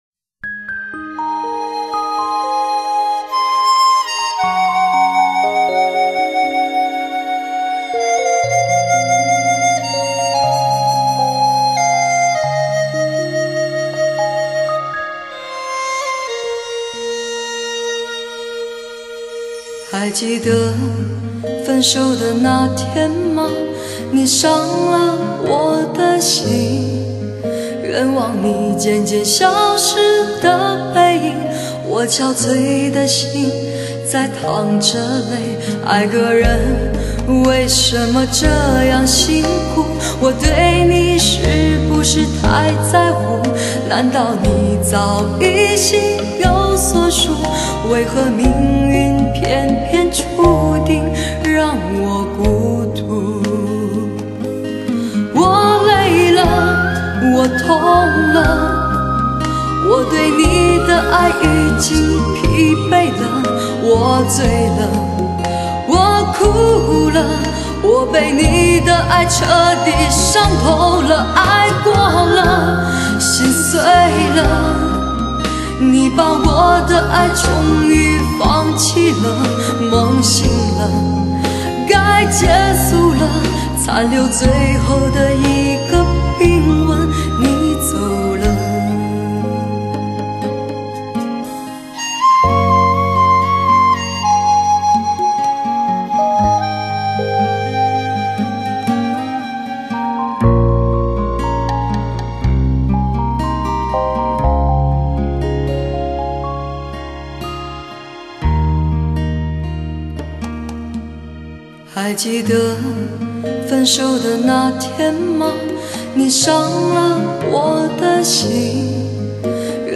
致力于有限空间实现最佳音效。